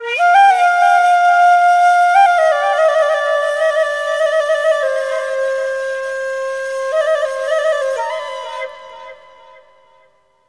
EASTFLUTE1-R.wav